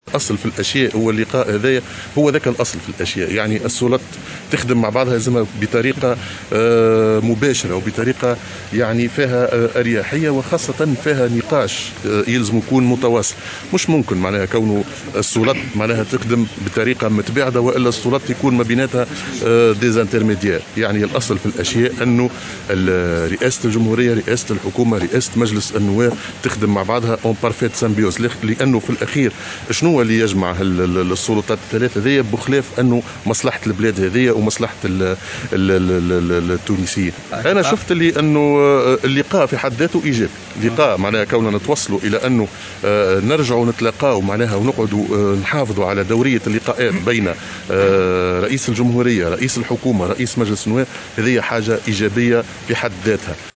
علق رئيس الحكومة هشام المشيشي, في تصريح لمراسل الجوهرة "اف ام" اليوم الجمعة على اللقاء الذي جمع بين رئيس الجمهورية ورئيس البرلمان يوم أمس.